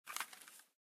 reload_start.ogg